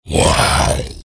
戦闘 （163件）
クリーチャーボイス2.mp3